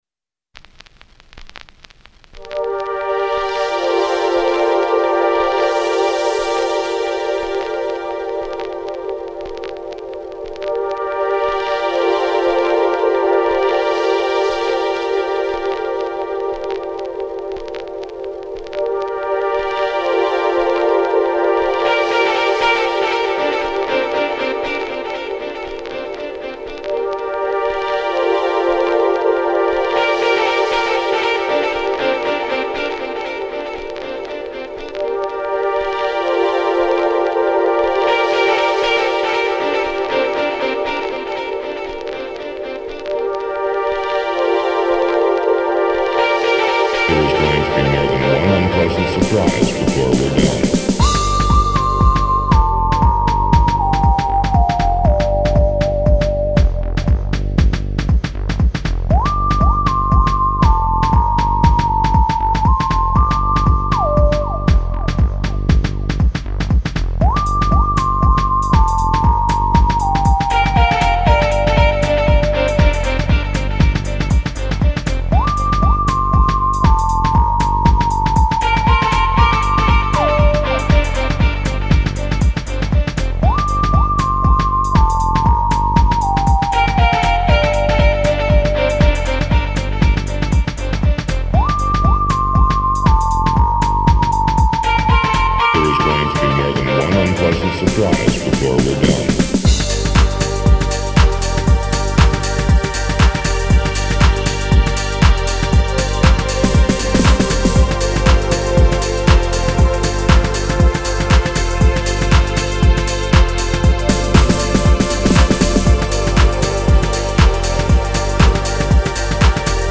finest electronic music.